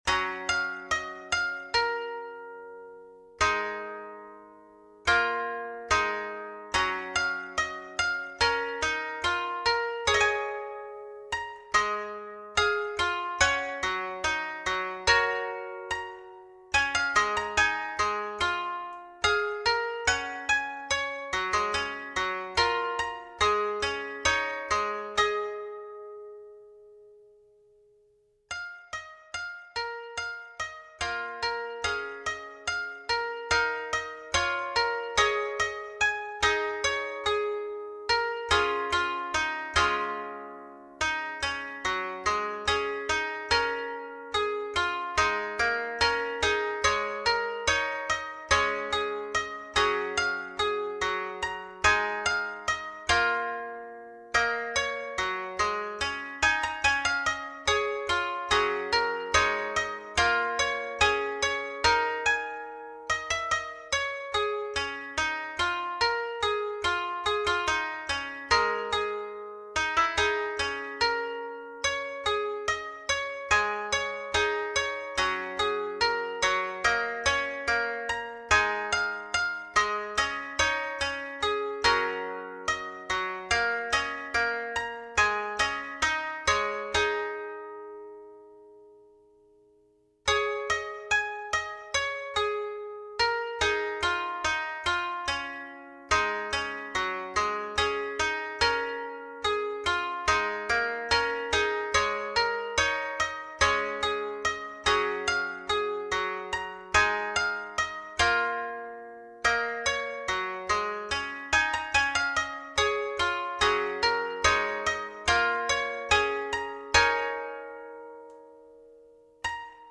「２パート同一調絃」「五音階を基調とする」という条件で、箏二重奏の作曲を試みました。